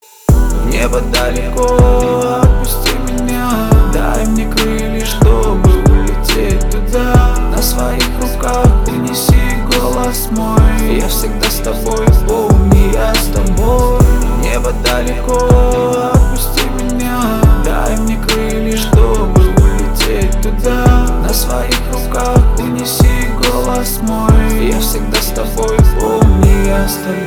• Качество: 320, Stereo
мужской голос
лирика
грустные
русский рэп
мелодичные